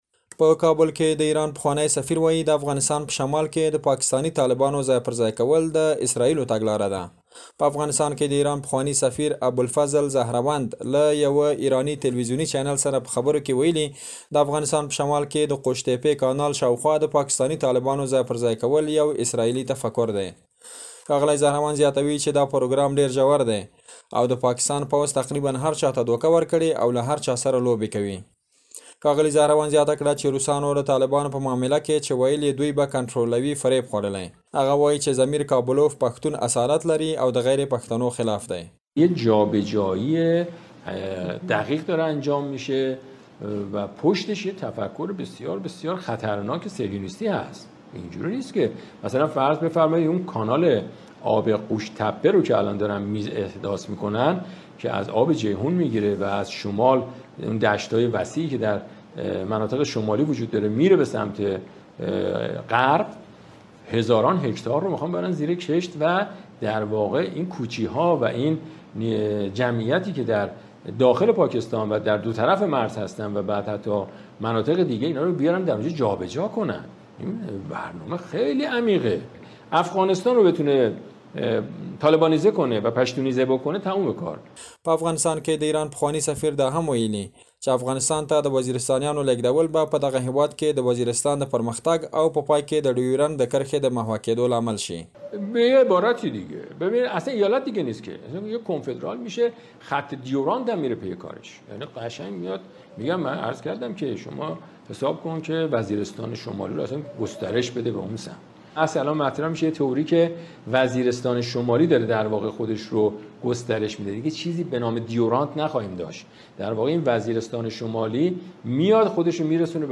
په افغانستان کې د ایران پخواني سفیر ابوالفضل ظهروند له يوه ايراني تلويزوني چينل سره په خبرو کې ويلي، د افغانستان په شمال کې د قوش تپې کانال شاوخوا د پاکستاني طالبانو ځای پر ځای کول يو اسرائیلي تفکر دی.